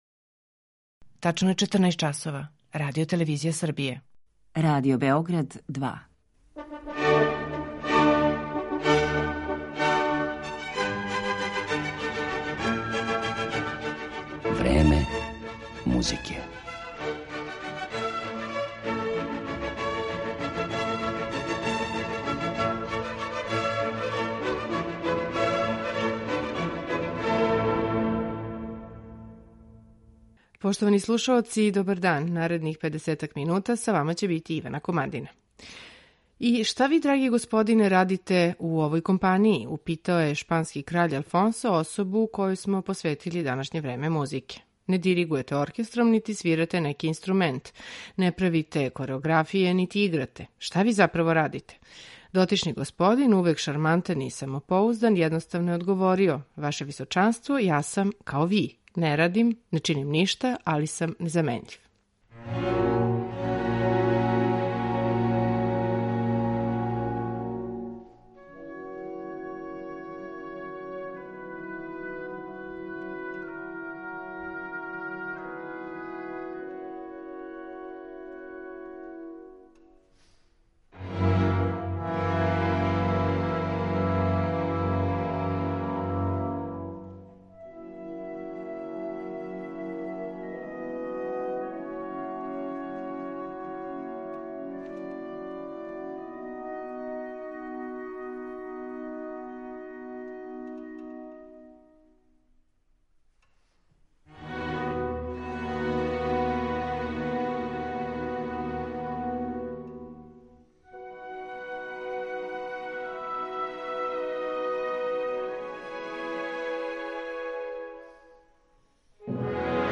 Идентитет овог човека који је оставио изузетно снажан печат на уметност првих деценија 20. века открићемо уз музику која је неодвојиво везана уз његову снажну ауторску личност: дела Даријуса Мијоа, Ерика Сатија и Жака Офенбаха.